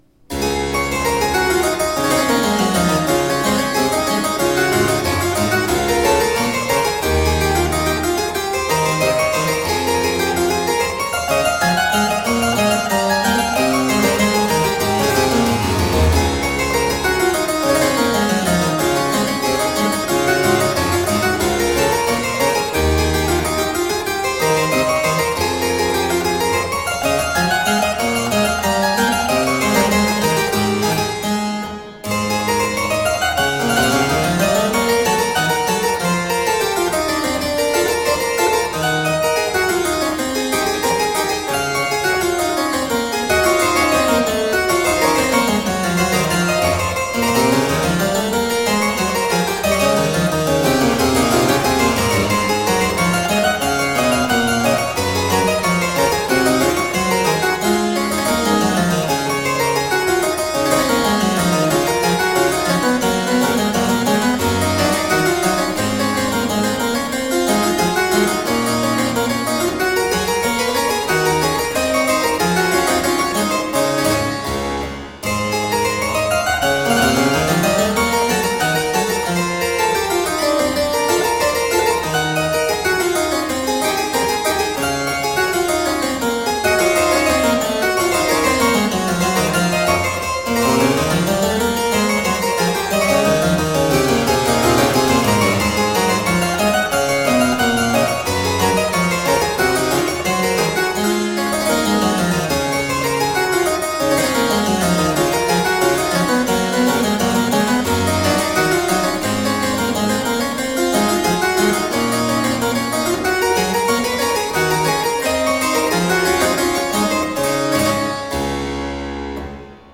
Bach on the harpsichord - poetic and expressive.